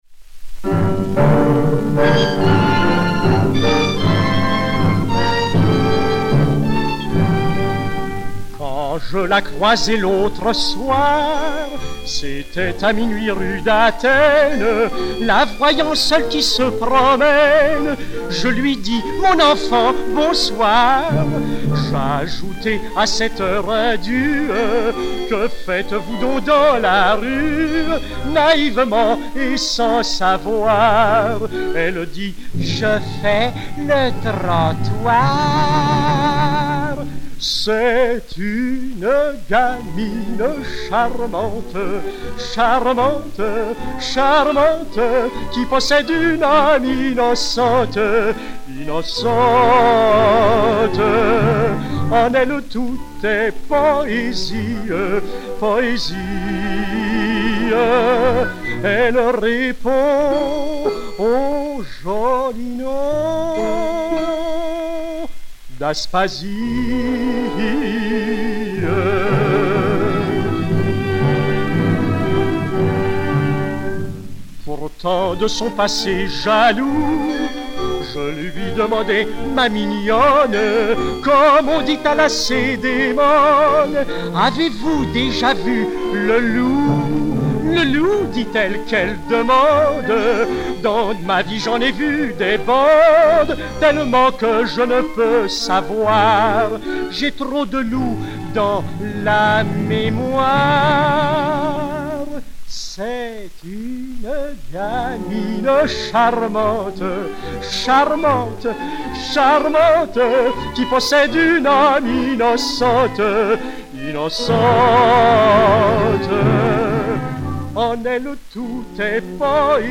Orchestre